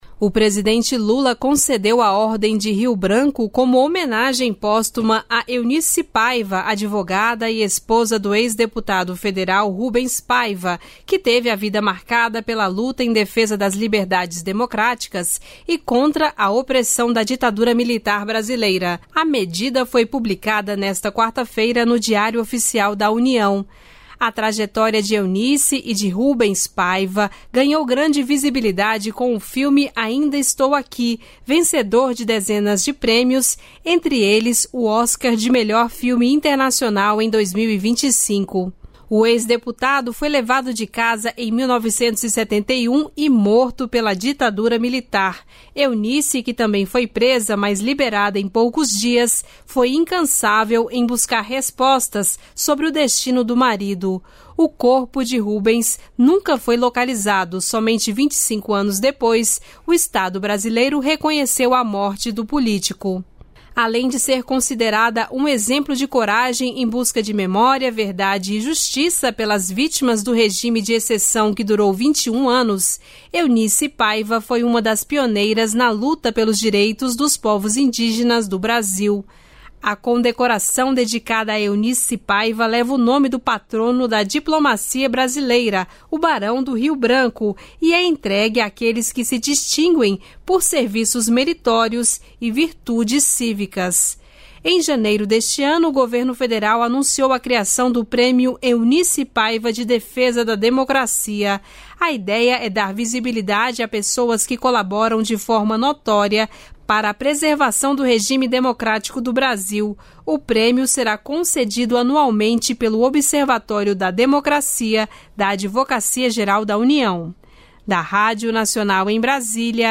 Rádio Nacional